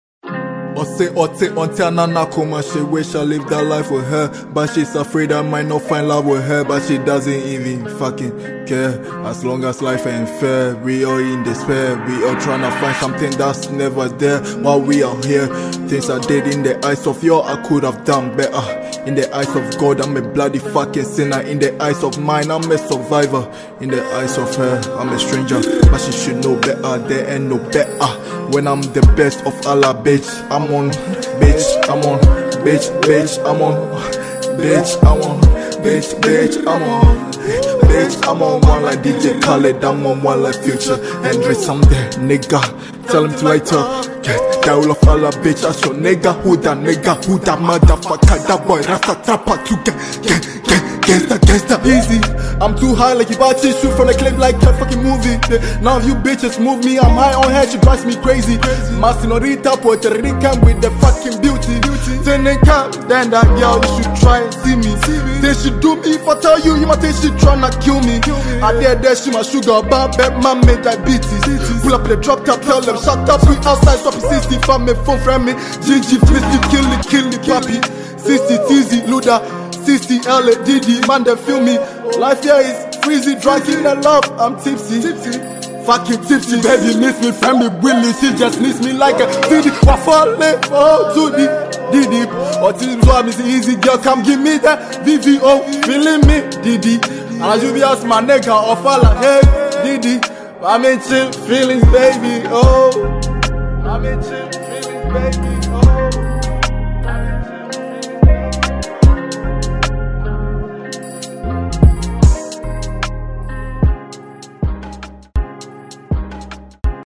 drill song